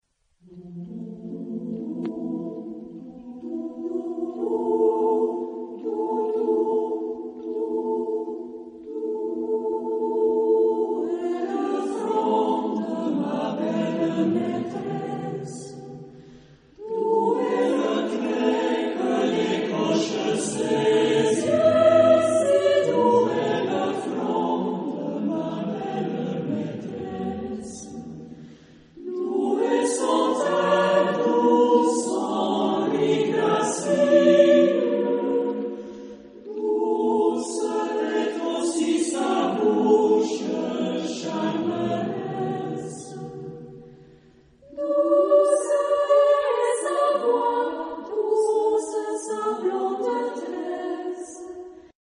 Genre-Style-Form: Secular ; Contemporary ; Partsong
Mood of the piece: fast ; joyous ; supple ; change of beat
Type of Choir: SATB  (4 mixed voices )